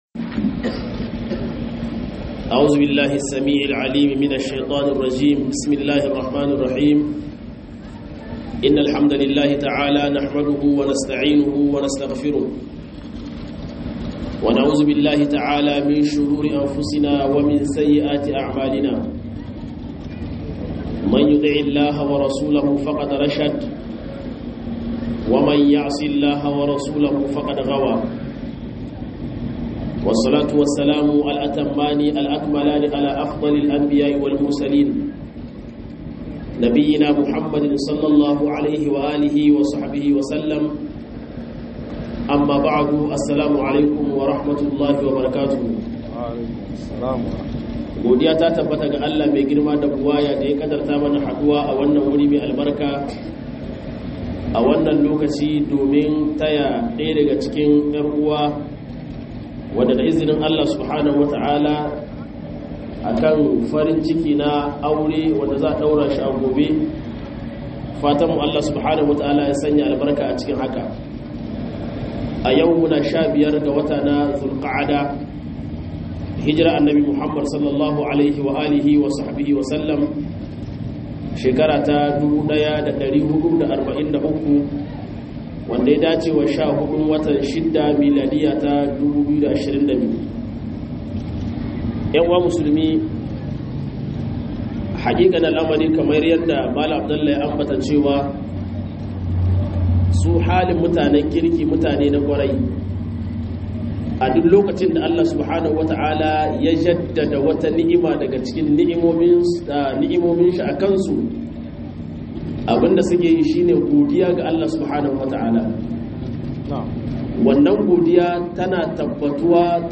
RAYUWAR MA'AWRATA - MUHADARA